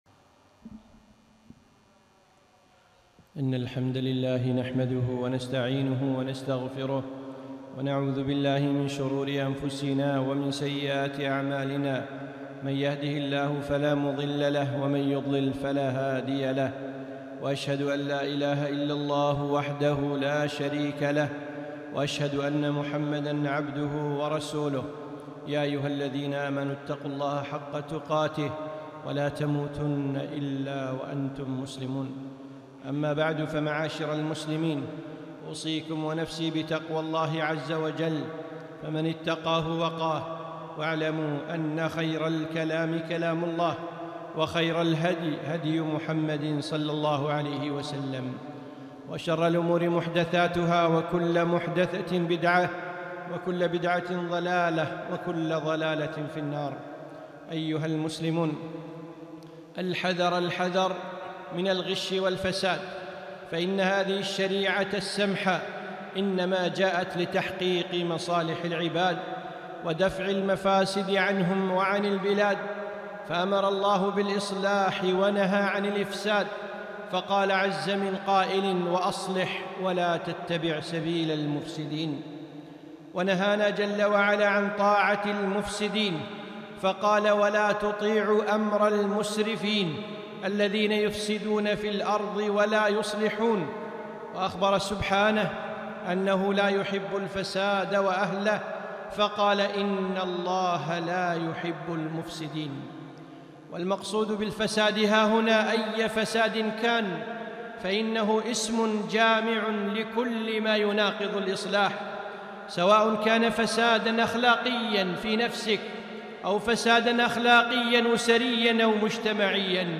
خطبة - التحذير من الغش والفساد